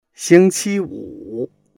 xing1qi1wu3.mp3